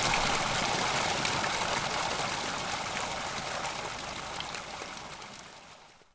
fountain.wav